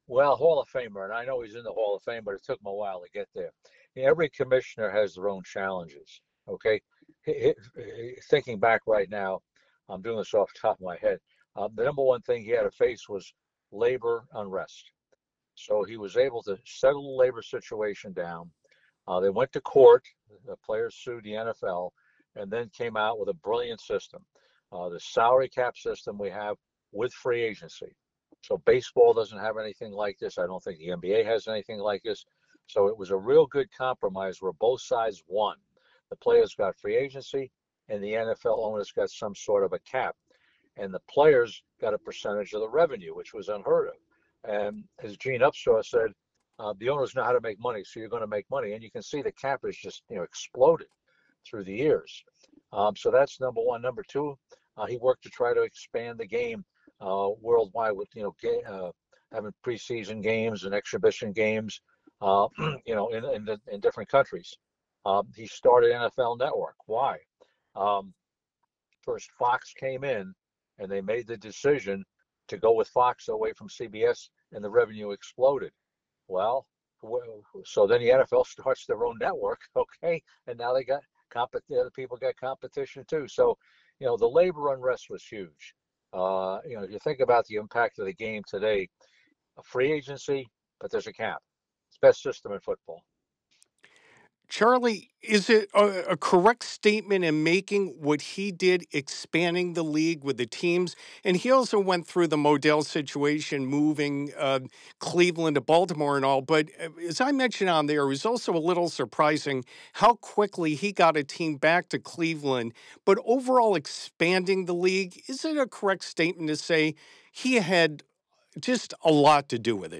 Charley Casserly, the former general manager of Washington's football team, joins WTOP to discuss how former NFL Commissioner Paul Tagliabue was really like.
This interview has been lightly edited for clarity.